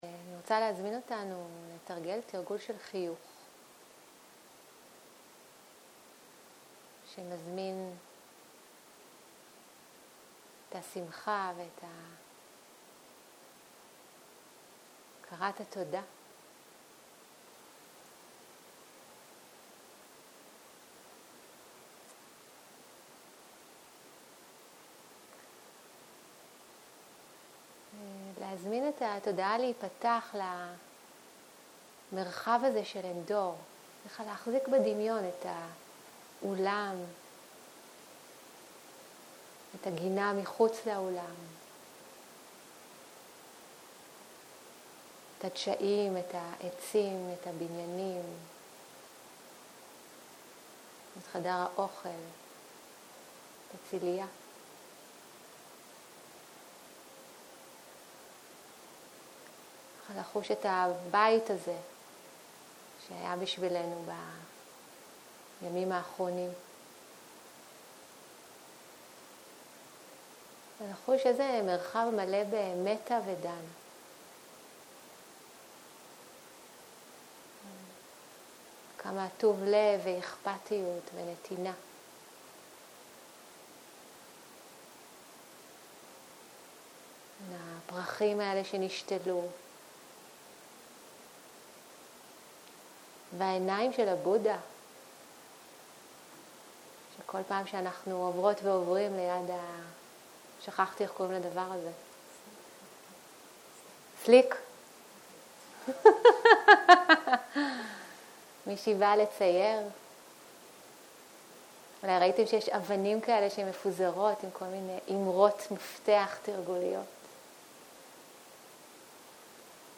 סוג ההקלטה: מדיטציה מונחית